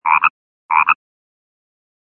Frogs.mp3